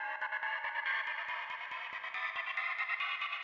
MB Trans FX (8).wav